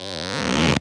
foley_leather_stretch_couch_chair_19.wav